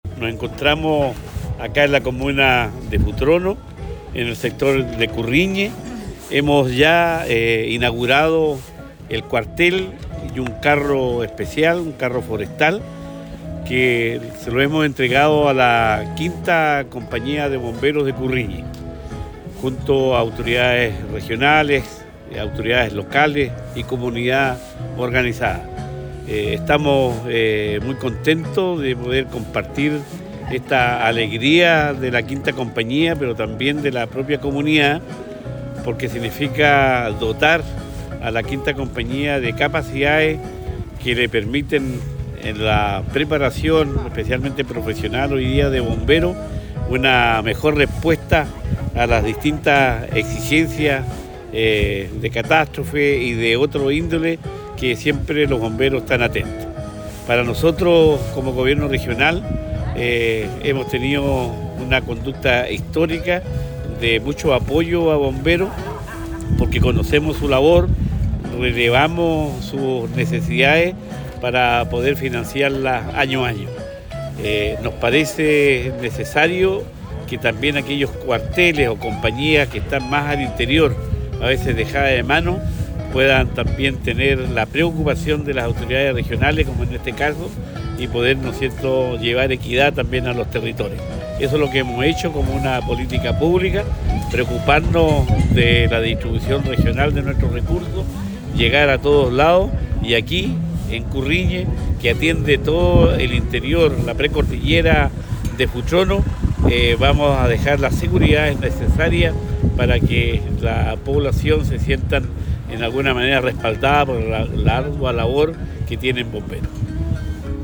Cuña_Gobernador-Cuvertino_Cuartel-Bomberos-Curriñe.mp3